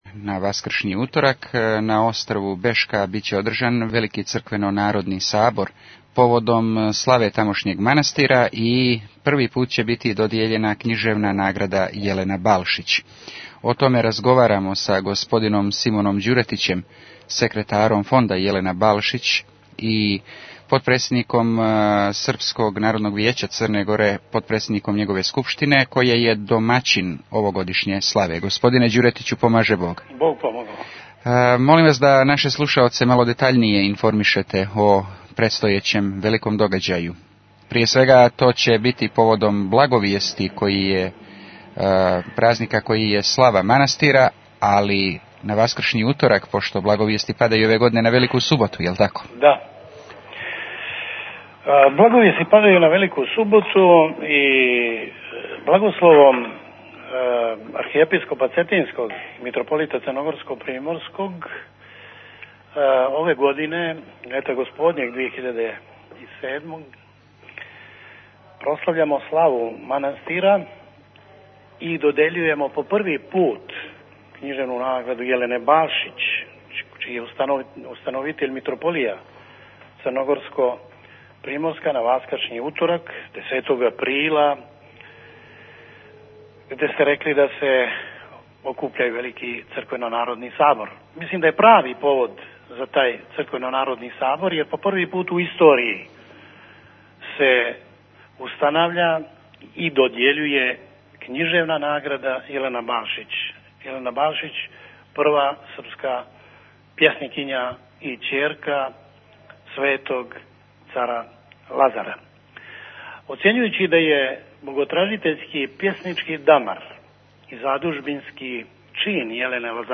Актуелни разговори (2324) | Радио Светигора